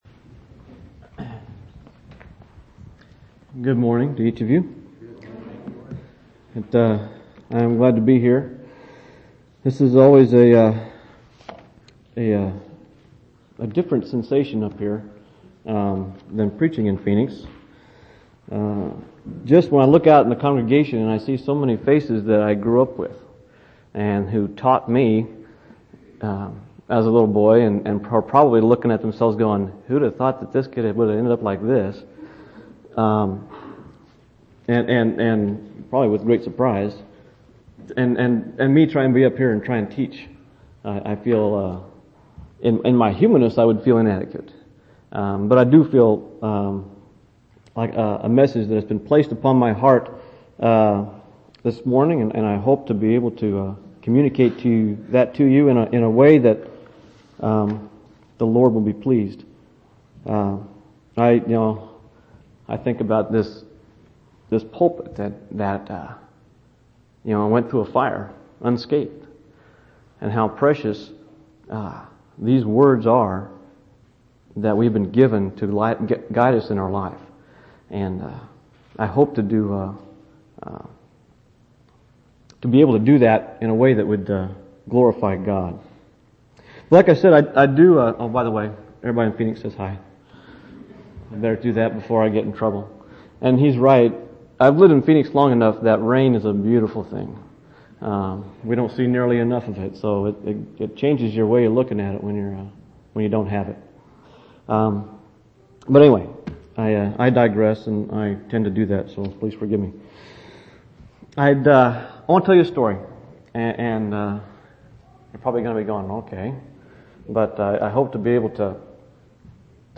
2/13/2005 Location: Temple Lot Local Event